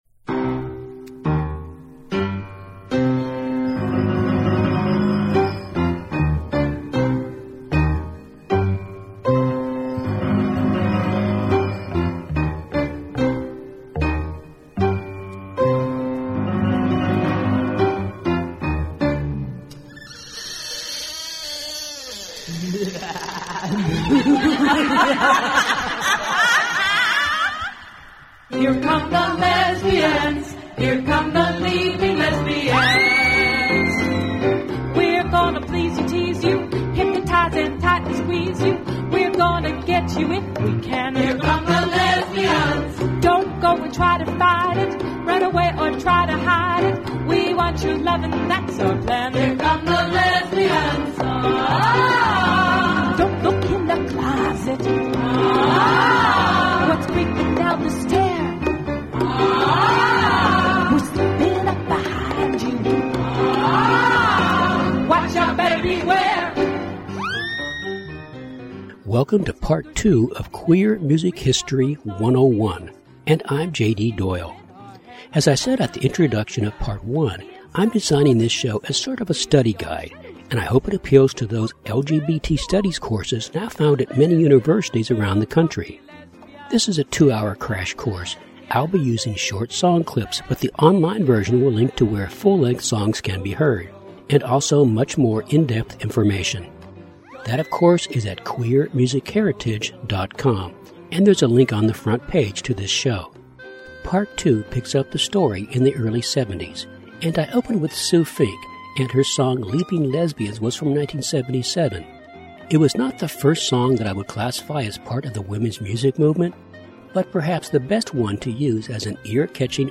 I'll be using short song clips, 65 of them, but in this section I have links to full versions of the songs, and also to more in-depth information.